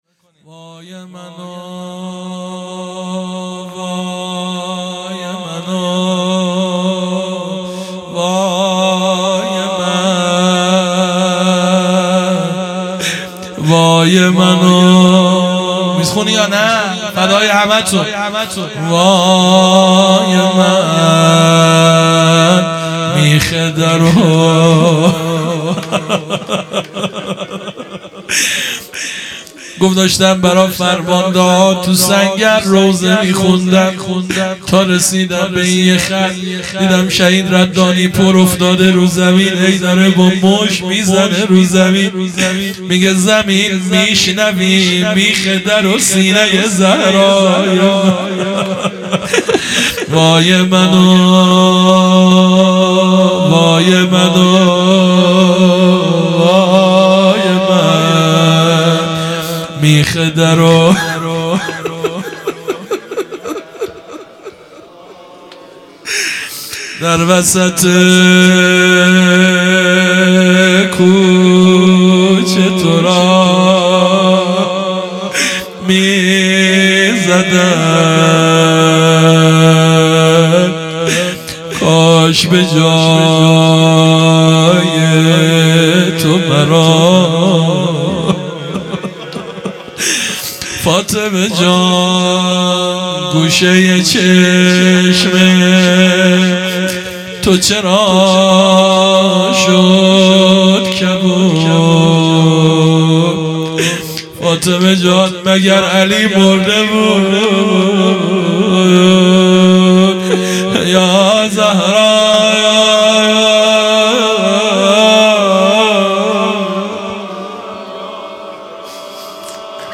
هیئت مکتب الزهرا(س)دارالعباده یزد - روضه
فاطمیه 1401_شب دوم